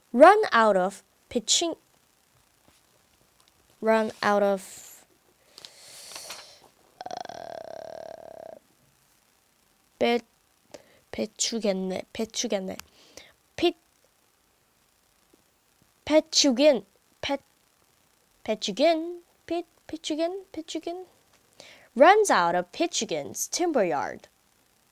pitchugin_blooper.mp3